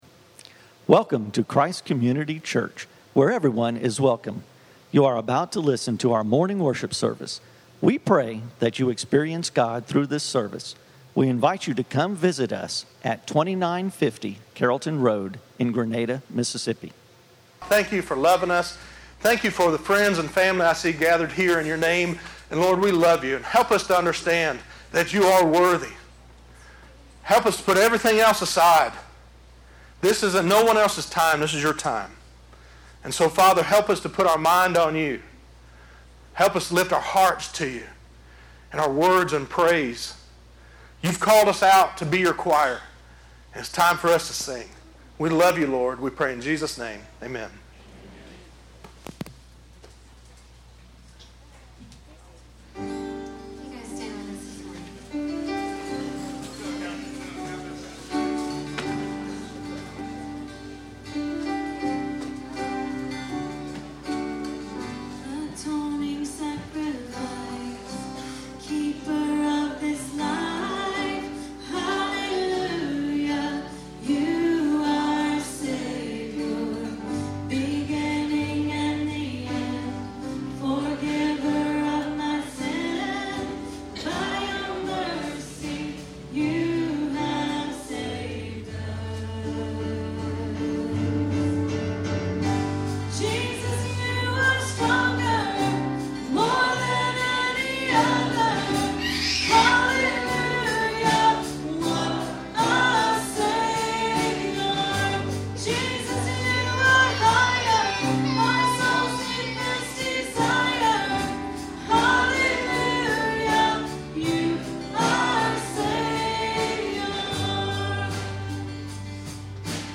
YES LORD - Messages from Christ Community Church. Contact us for more information